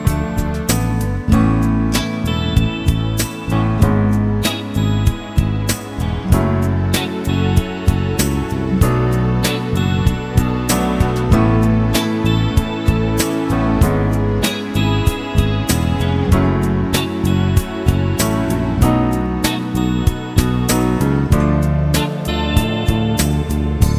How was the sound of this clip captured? Live In Chile 2009 Pop (1980s) 3:59 Buy £1.50